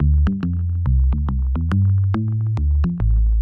键盘SA10音色库 " 40电贝司
描述：电贝司
Tag: 贝司 C 简单